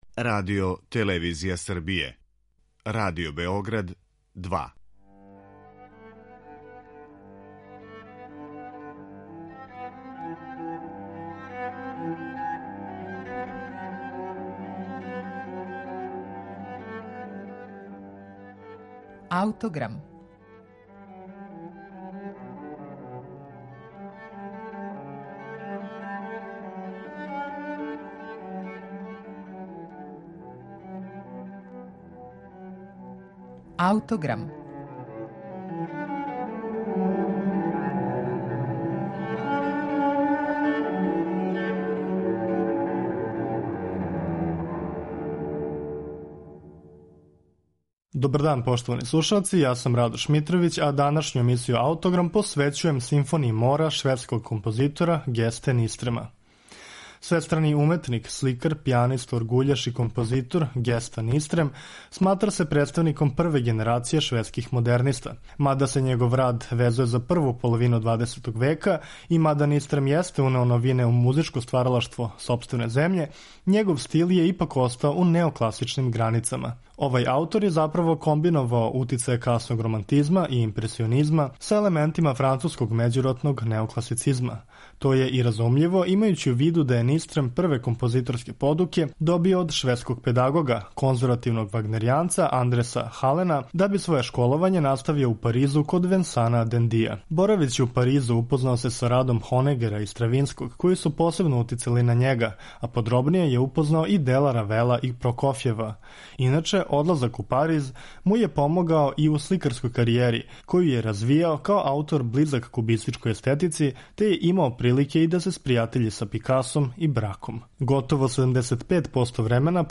његов стил је ипак остао у неокласичним границама.